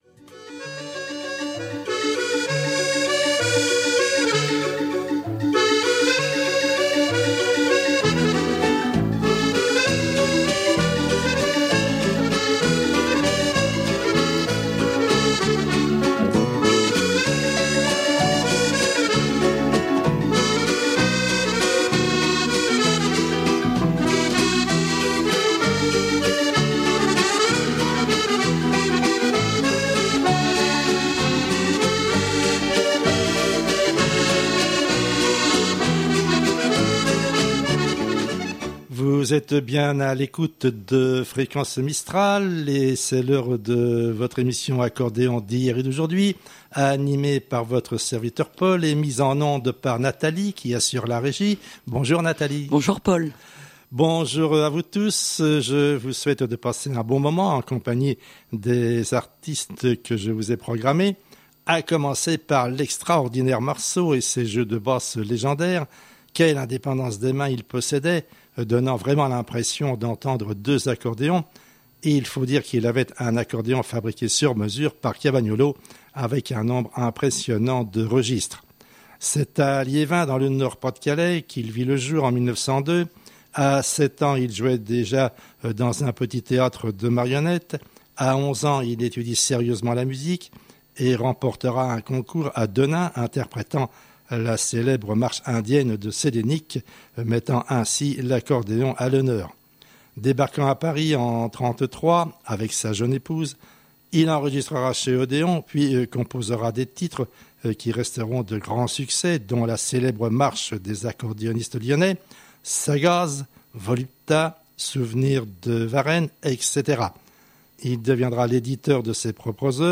Accordéon d'hier et d'aujourdhui du 18 avril 2015